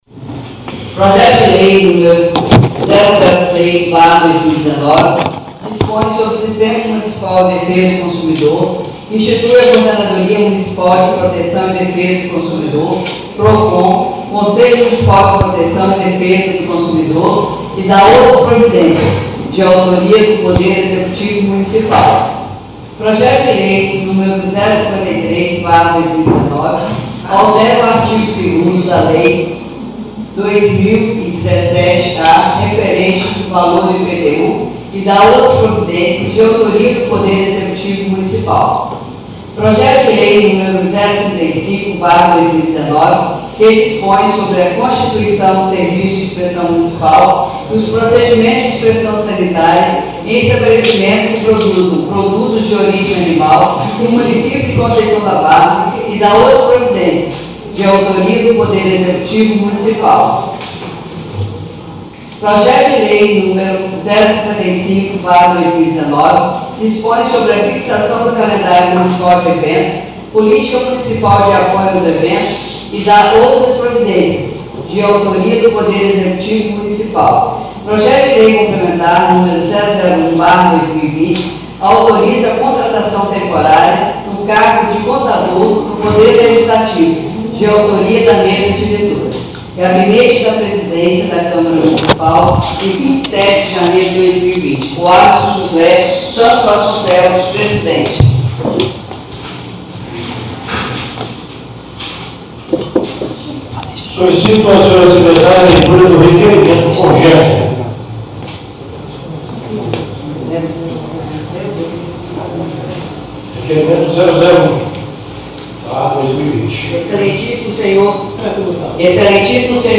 1ª Sessão Extraordinária do dia 29 de janeiro de 2020